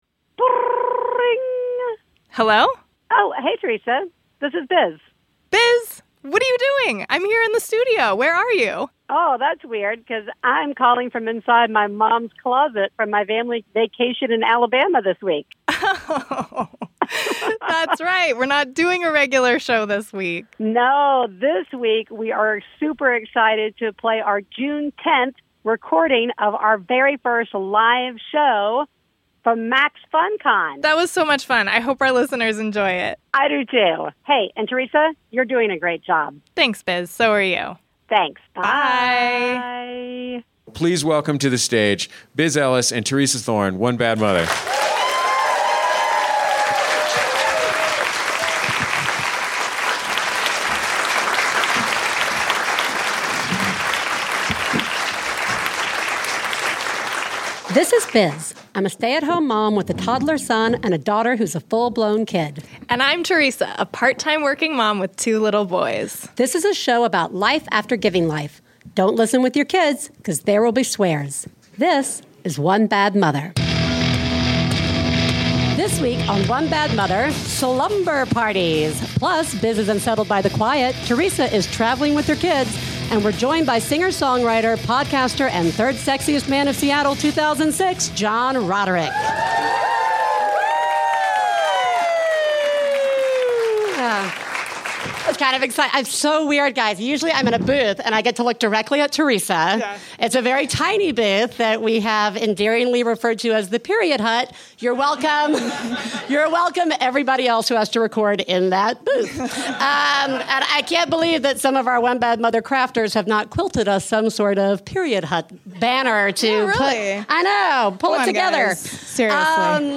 Comedy
This episode was recorded live at MaxFunCon on June 12th!